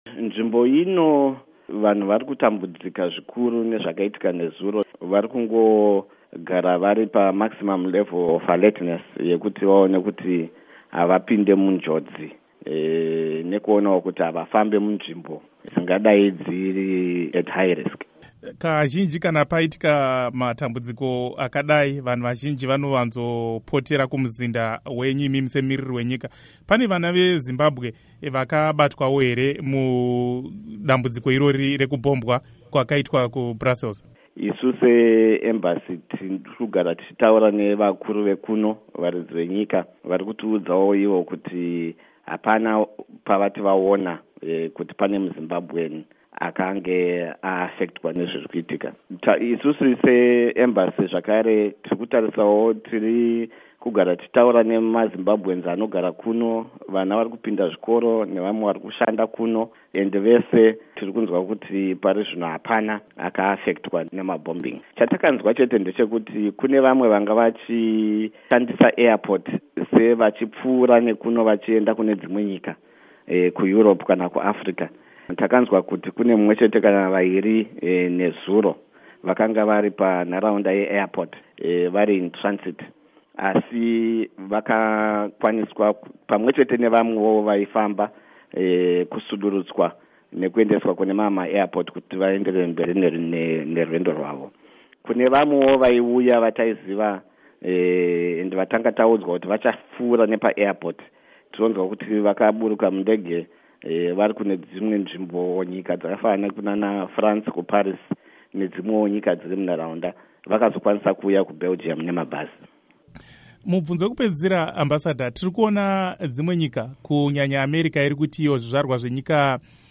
Hurukuro neMumiri weZimbabwe kuBelgium VaTadeous Tafirenyika Chifamba